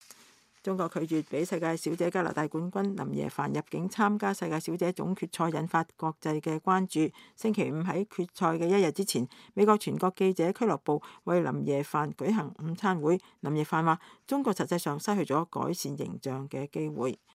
加拿大世界小姐林耶凡星期五受邀參加華盛頓國家記者俱樂部為她舉辦的午餐會上談到她被中國拒絕入境參加世界小姐大賽：“我覺得這次的輸家不是我，是中國政府沒有借這次机會給自己樹立一個正面的形象。”